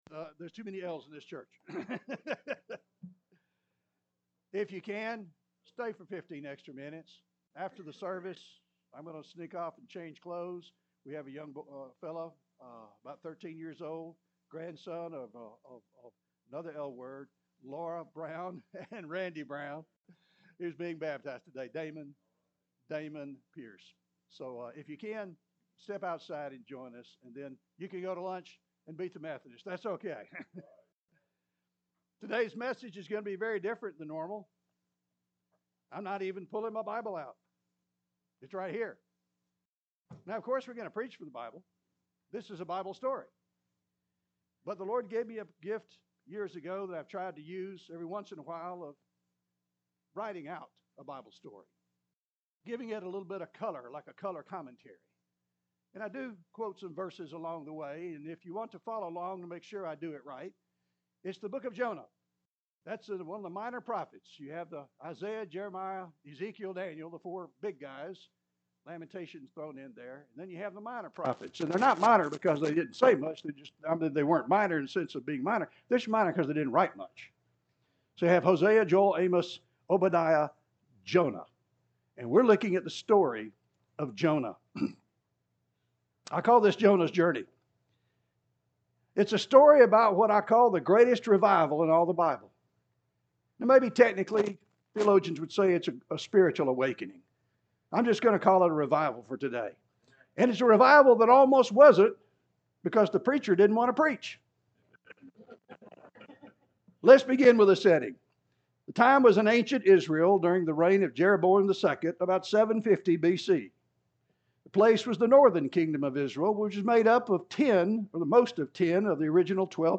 Story Sermons